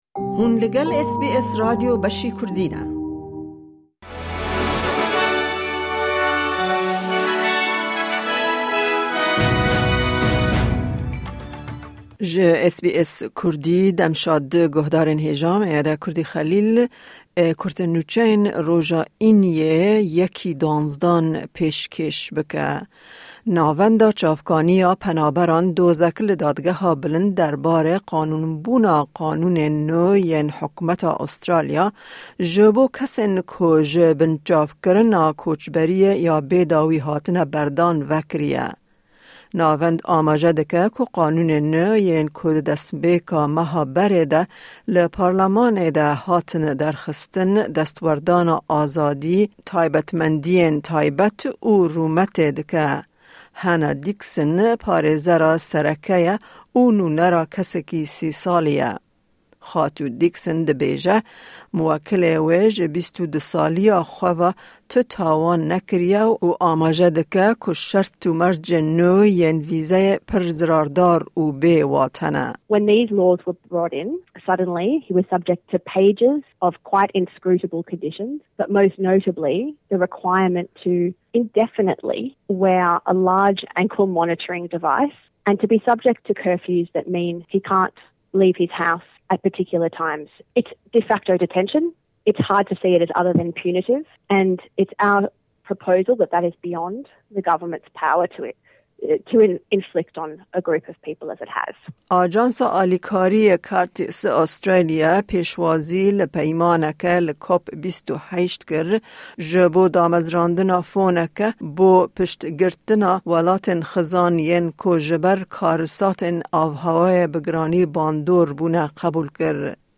Newsflash